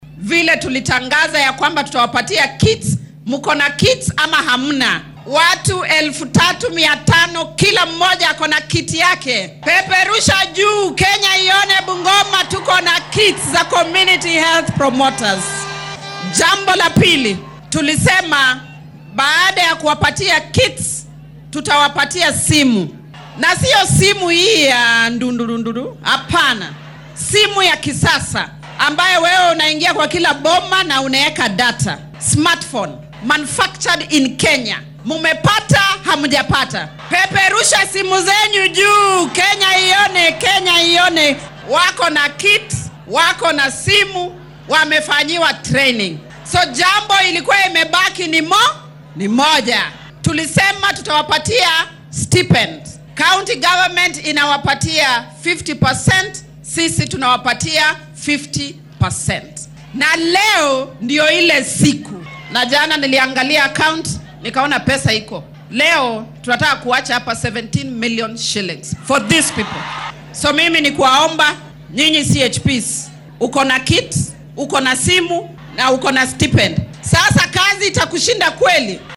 DHAGEYSO:Wasiiradda caafimaadka oo ka hadashay lacago la siinaya dadka si iskood ah uga shaqeeya caafimaadka
Xilli ay arrimahan ka hadleysay iyadoo ku sugan ismaamulka Bungoma ee galbeedka dalka ayay wasiiradda caafimaadka ee Kenya tiri.